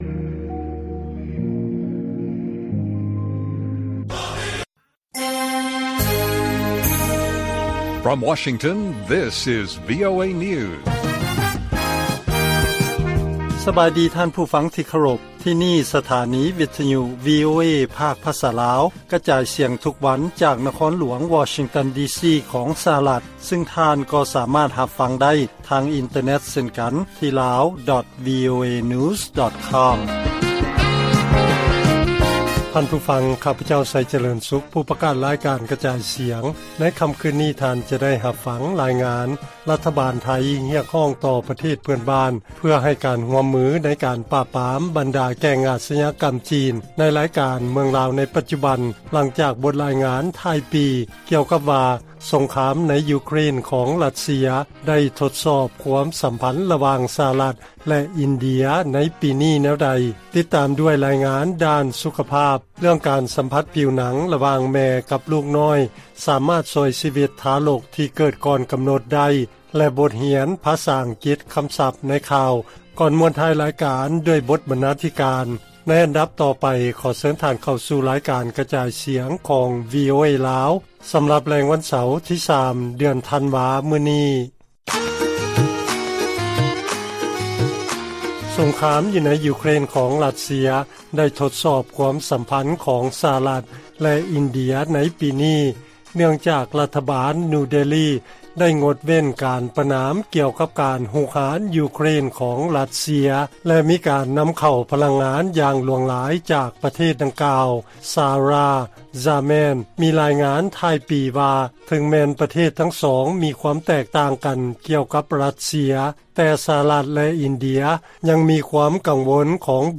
ລາຍການກະຈາຍສຽງຂອງວີໂອເອລາວ: ສະຫະລັດ ແລະອິນເດຍ ມີຄວາມເຫັນແຕກຕ່າງກັນໃນເລື້ອງຣັດເຊຍ ແຕ່ກໍມີຄວາມກັງວົນຮ່ວມກັນ ກ່ຽວກັບຈີນ